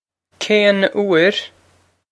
kane oor?
This is an approximate phonetic pronunciation of the phrase.